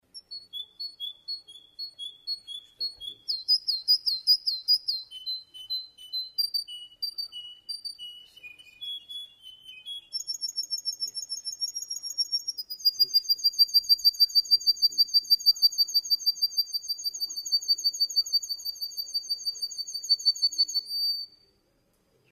Канарейка